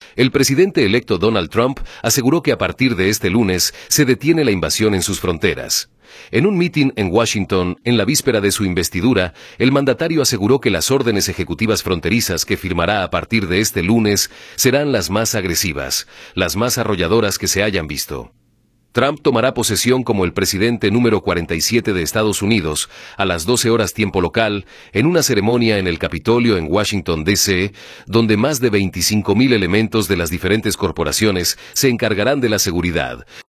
El presidente electo Donald Trump aseguró que a partir de este lunes se detiene la invasión en sus fronteras. En un mitin en Washington, en la víspera de su investidura, el mandatario aseguró que las órdenes ejecutivas fronterizas que firmará a partir de este lunes serán las más agresivas, las más arrolladoras que se hayan visto.